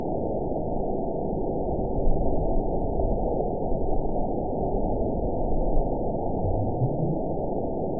event 911086 date 02/09/22 time 23:03:24 GMT (3 years, 10 months ago) score 9.41 location TSS-AB04 detected by nrw target species NRW annotations +NRW Spectrogram: Frequency (kHz) vs. Time (s) audio not available .wav